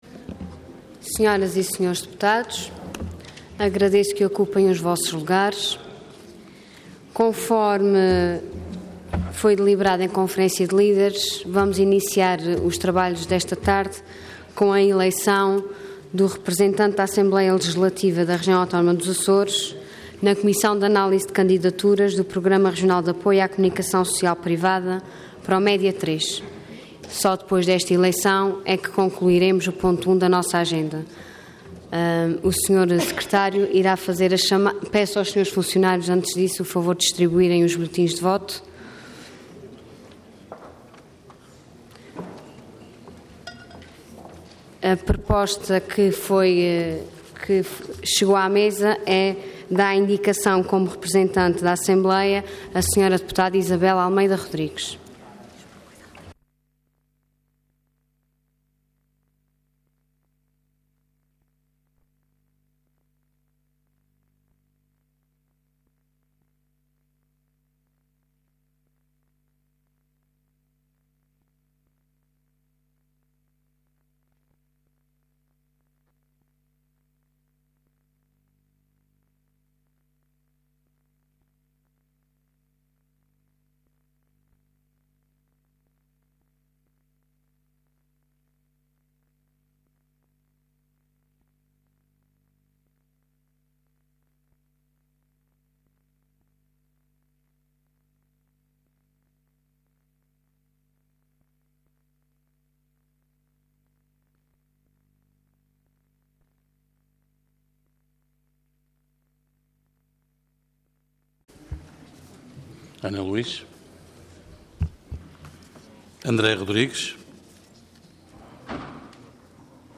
Intervenção Orador Ana Luísa Luís Cargo Presidente da Assembleia Regional Entidade ALRAA